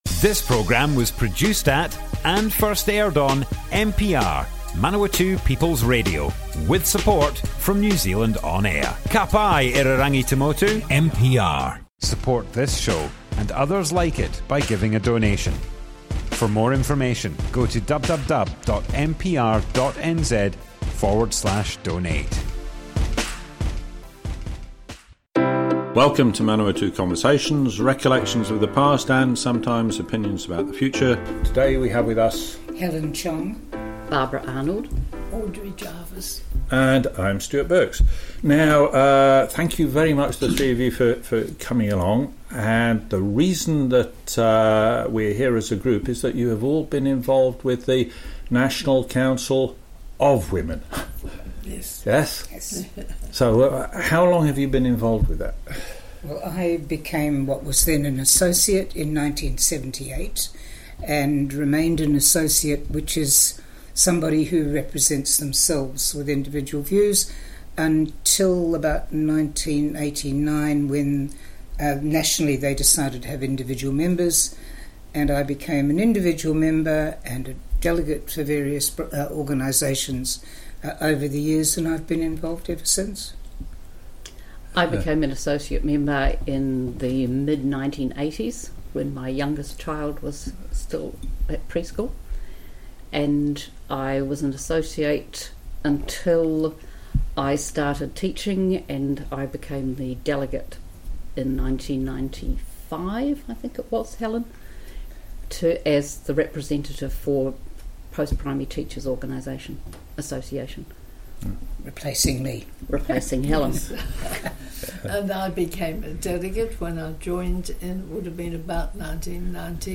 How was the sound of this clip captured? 00:00 of 00:00 Add to a set Other Sets Description Comments National Council, of Women, Part 1 - Manawatu Conversations More Info → Description Broadcast on Manawatu People's Radio, 28th December 2021.